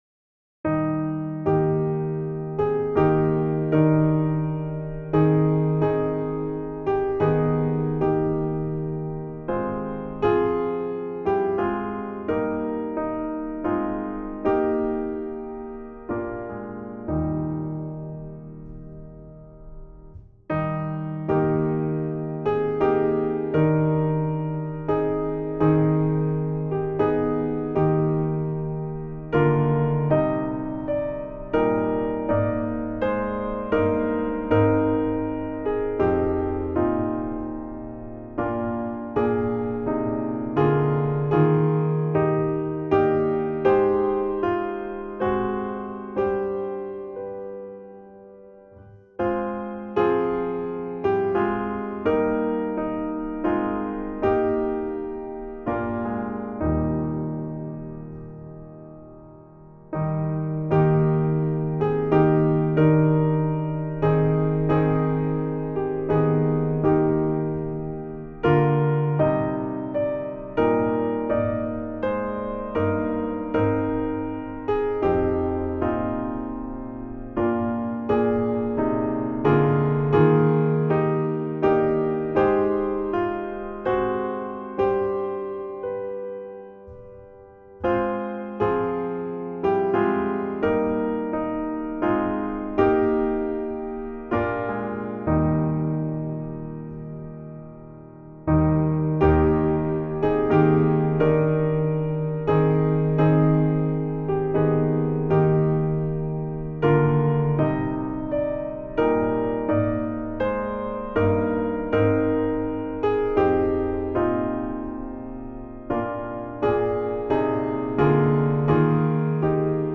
Voicing/Instrumentation: SATB , Choir Unison We also have other 1 arrangement of " Great God, To Thee My Evening Song ".